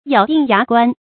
咬定牙關 注音： ㄧㄠˇ ㄉㄧㄥˋ ㄧㄚˊ ㄍㄨㄢ 讀音讀法： 意思解釋： 亦作「咬定牙根」。